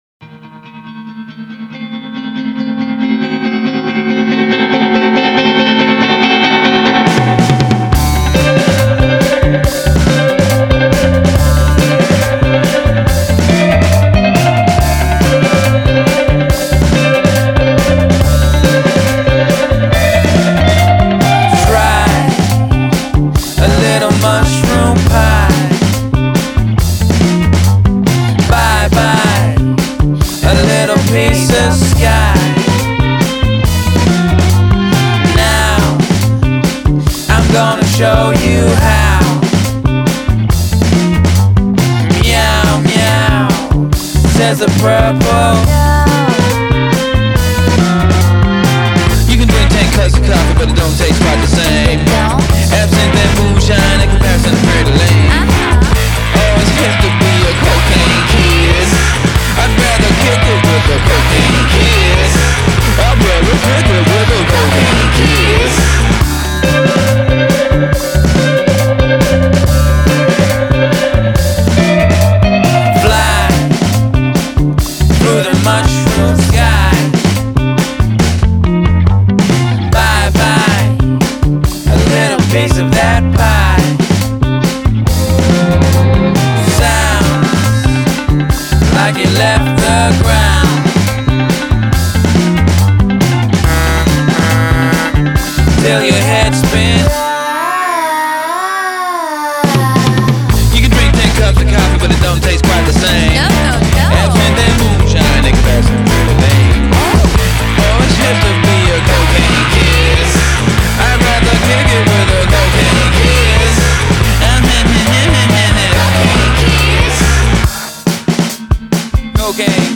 Genre: Electronic, Alternative, Downtempo